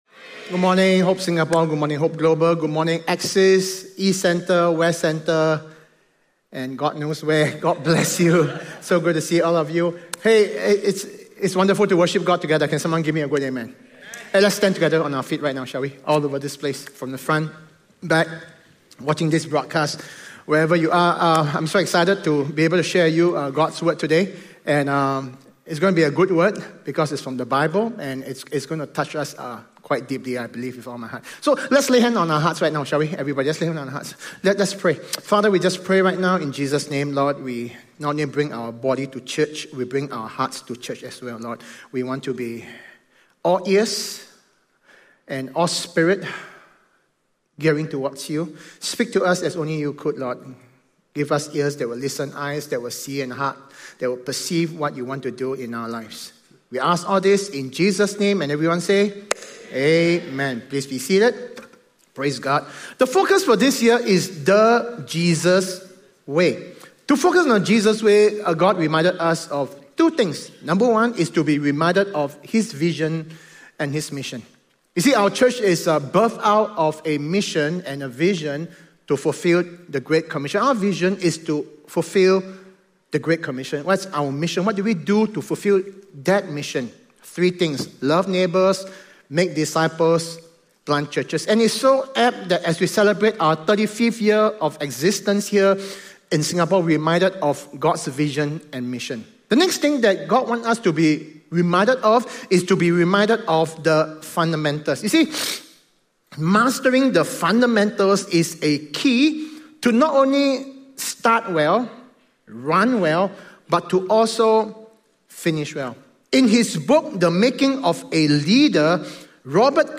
Perspective Questions: In the sermon, pride is linked with self-reliance.